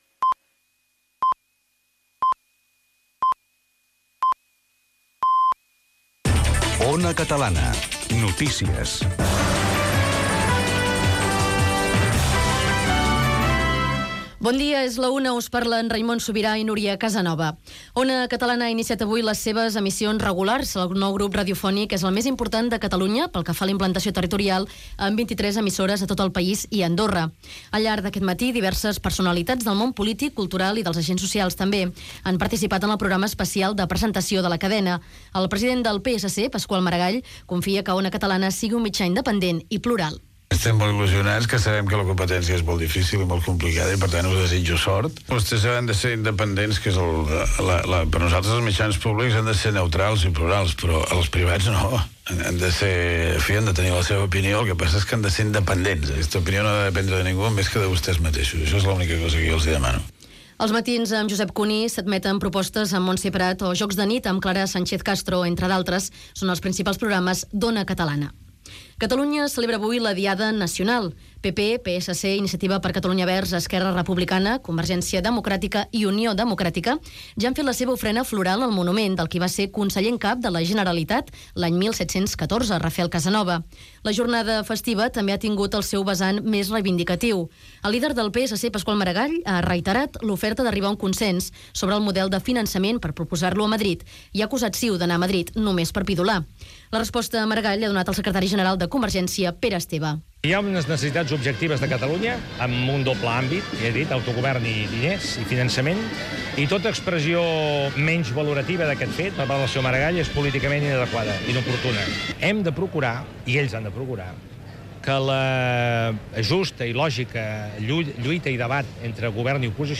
Careta del programa, inici d'emissions d'Ona Catalana (declaracions de Paqual Maragall), Diada Nacional de Catalunya (declaracions de Pere Esteve), Palestina, esports, indicatiu de la ràdio
Informatiu